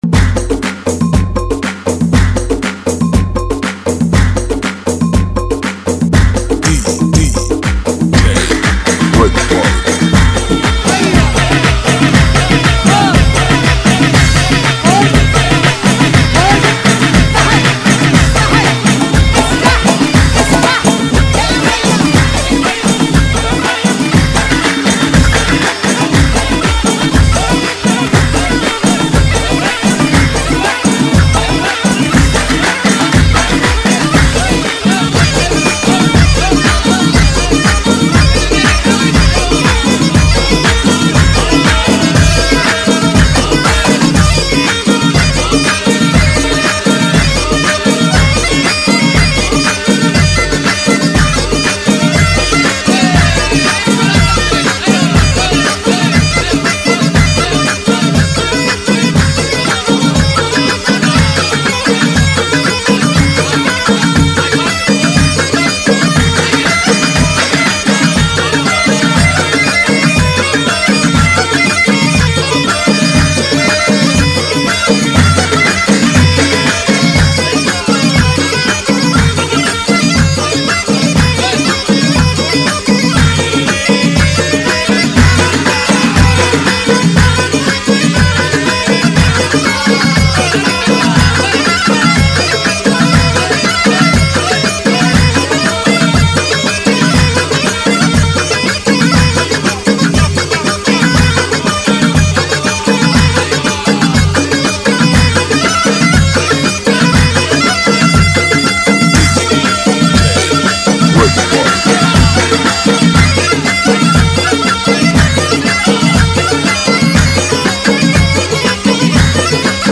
[ 118 BPM ]
ريمكس